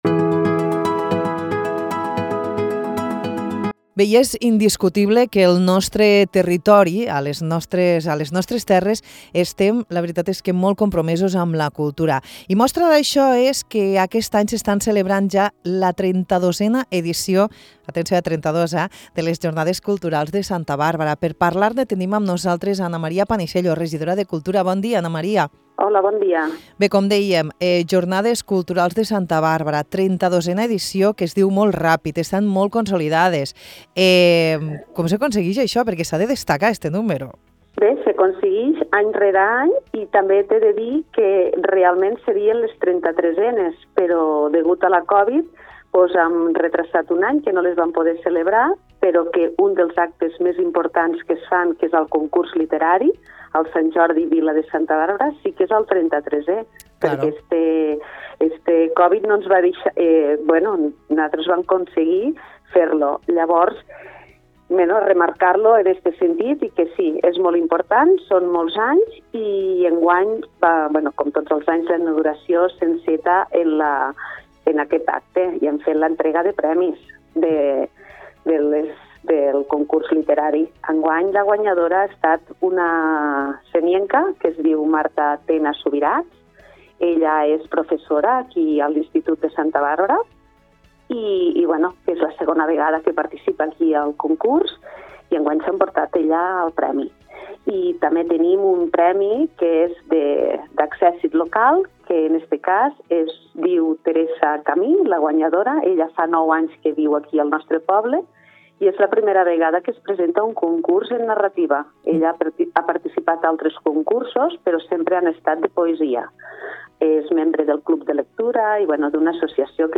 Ana Maria Panisello, regidora de Cultura de l’Ajuntament de Santa Bàrbara, fa un repàs a tot el més destacat d’aquesta 32a edició de les Jornades Culturals de Santa Bàrbara, que van començar el dia 20 d’abril i es podran gaudir fins al 7 de maig.